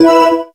SE_Select3.wav